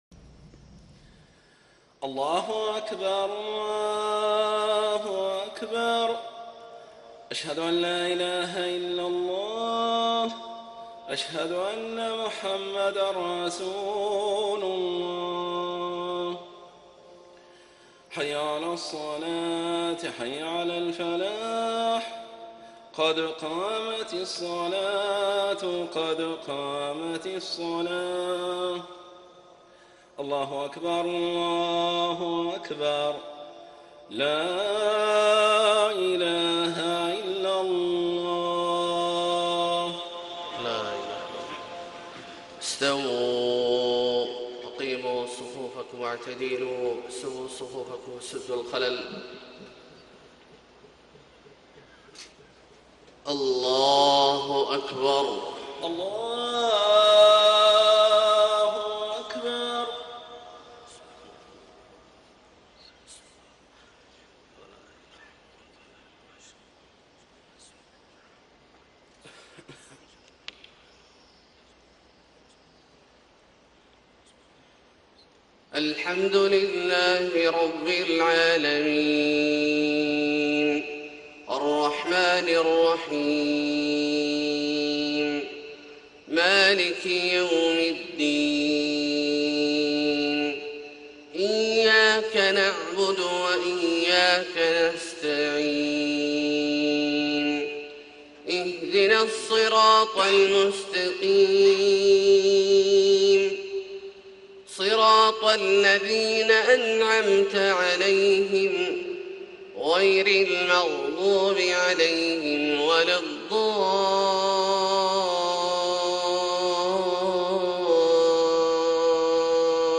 صلاة الفجر 9 - 1 - 1435هـ من سورة الإسراء > 1435 🕋 > الفروض - تلاوات الحرمين